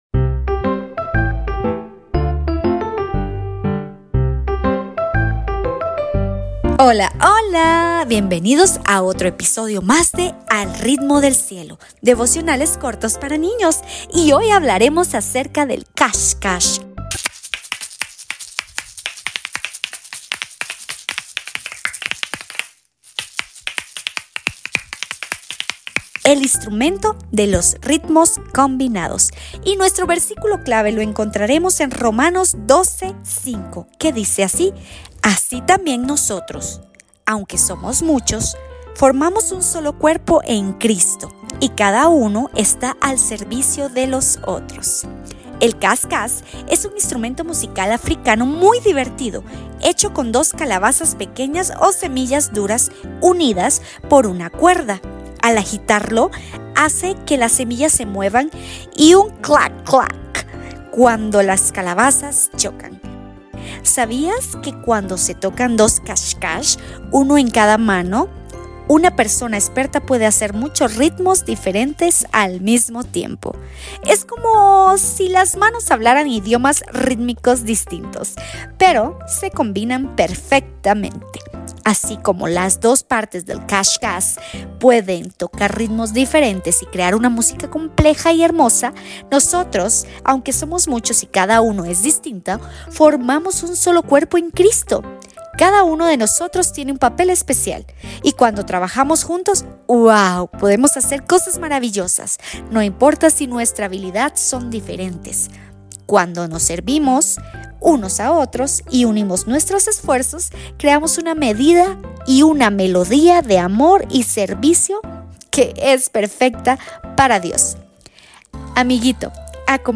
– Devocionales para Niños